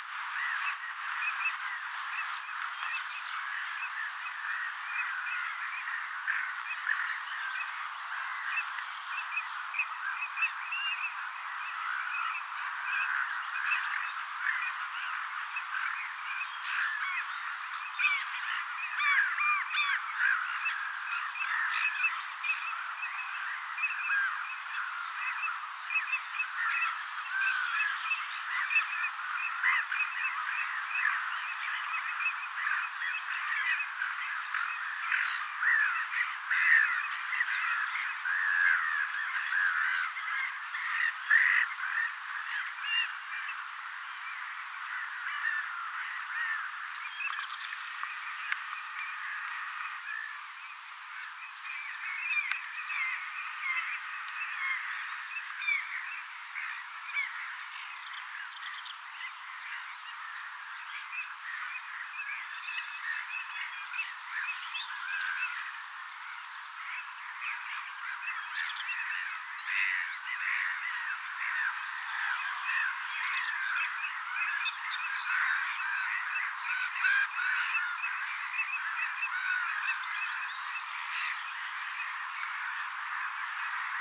pienen taviparven ääntelyä
pienen_taviparven_aantelya.mp3